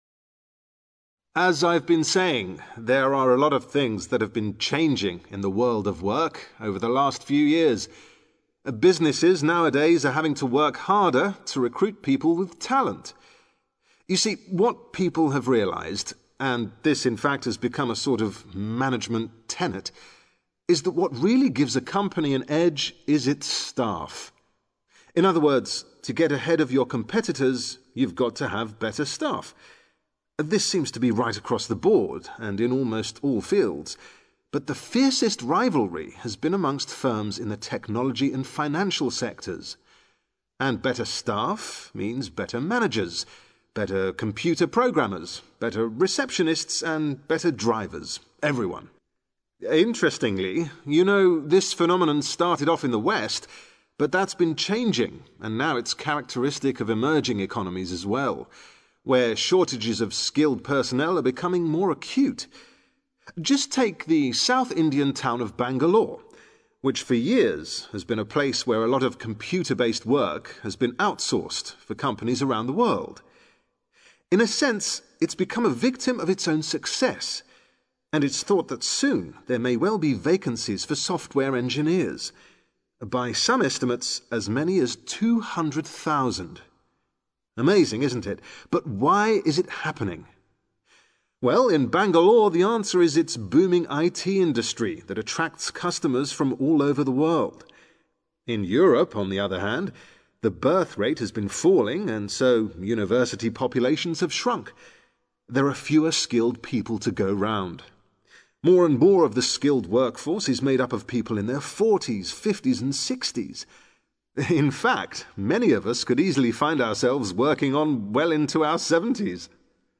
an economy expert talking about a skills shortage.